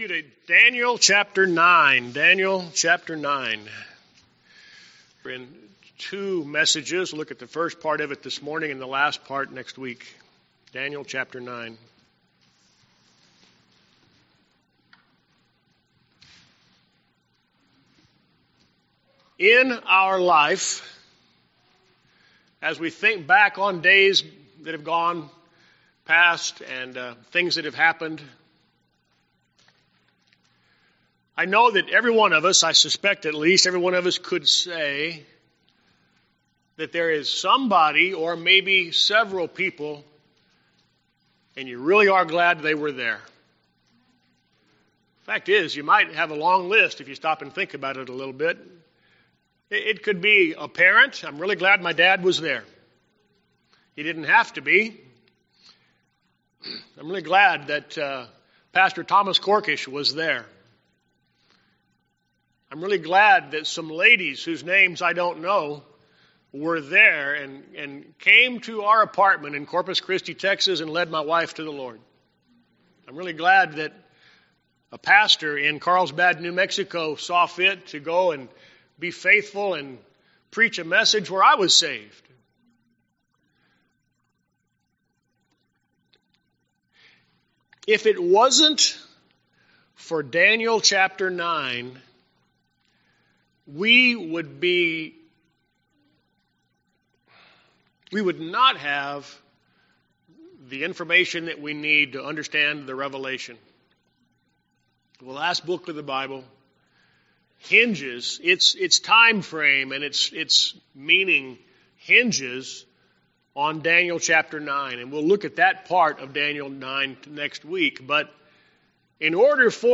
Fundamental Independent Baptist Church Glendale Arizona Luke AFB
Sermons